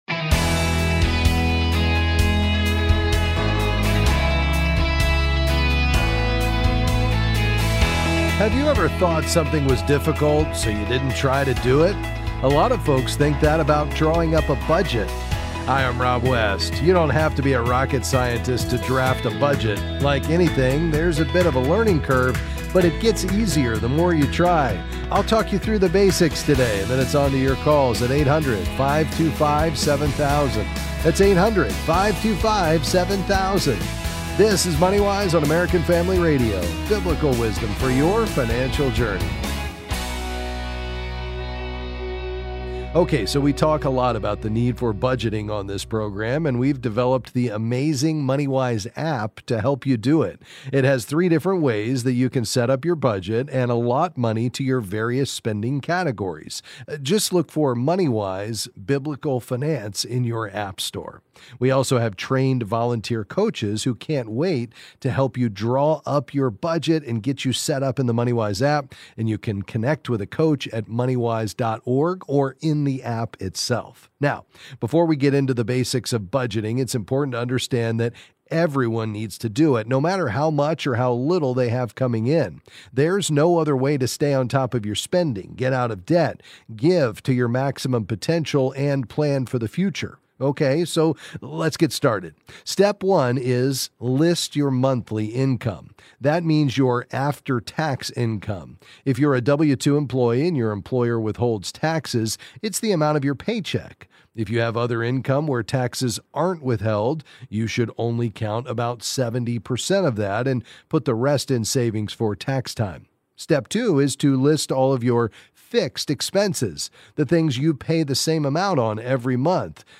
Then he’ll answer your questions on a variety of financial topics.